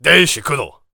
Song: VO warrior 1 conversation emotion angry3